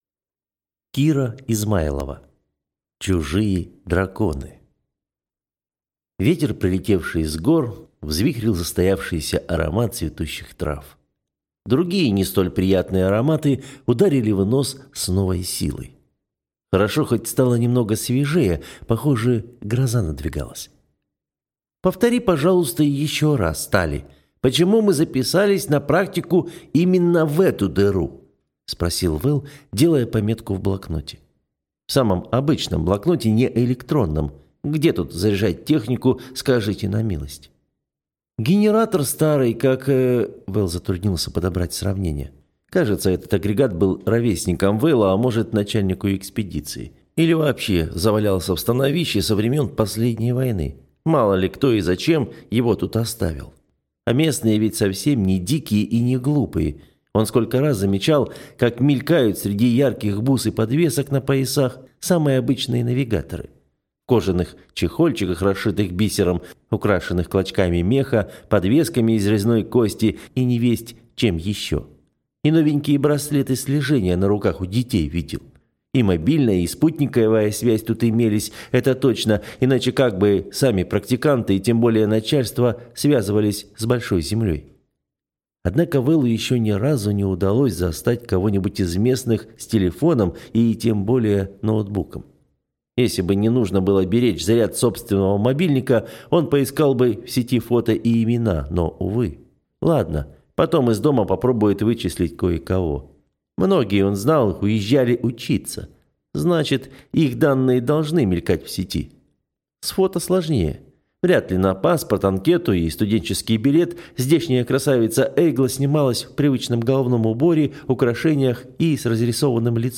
Аудиокнига Чужие драконы | Библиотека аудиокниг